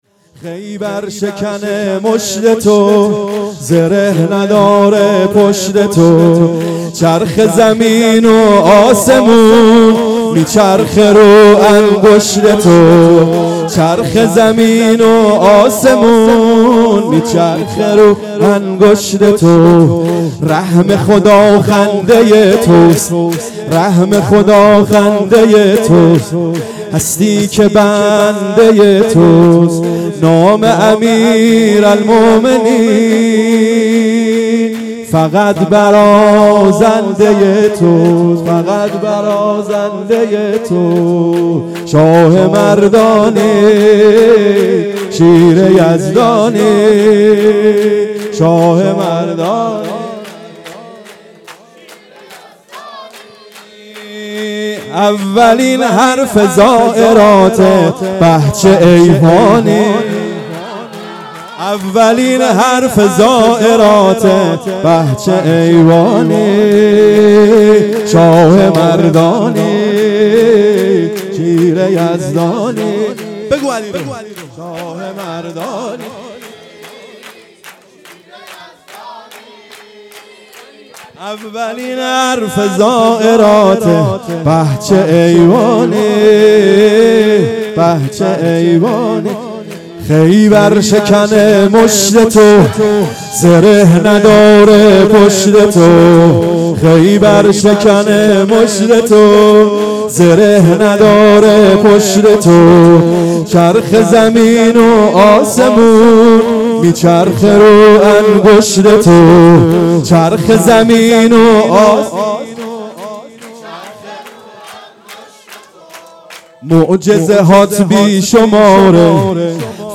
جشن ولادت حضرت زهرا سلام الله علیها